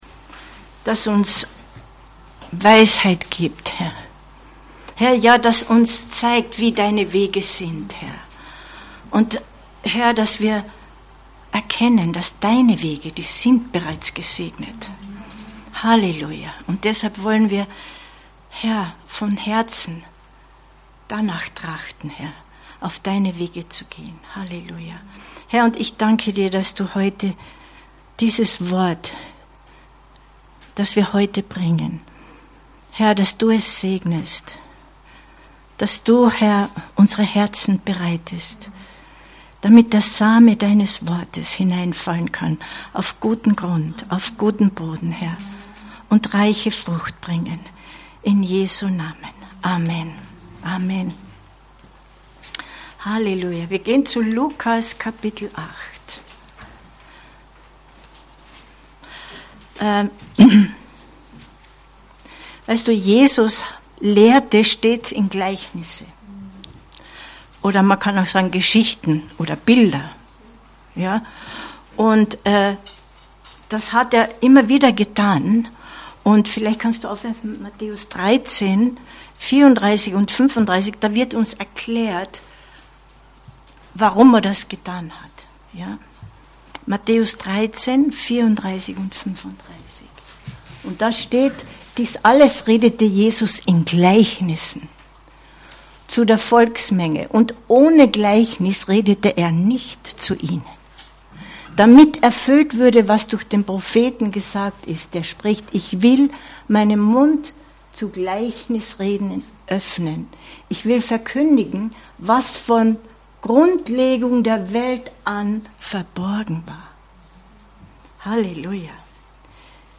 Info Info Frucht bringen in Geduld 07.05.2023 Predigt herunterladen